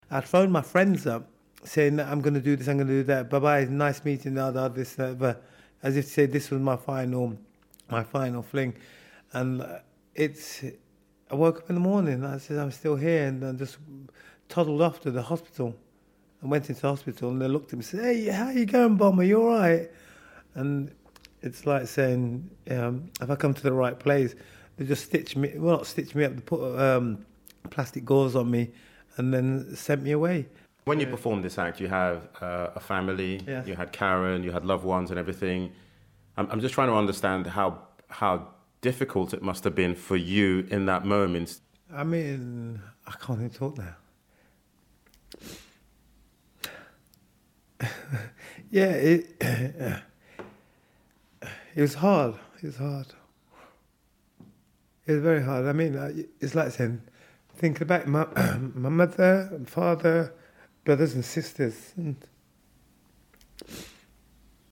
Hear the full interview on BBC Sportshour